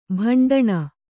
♪ bhaṇḍaṇa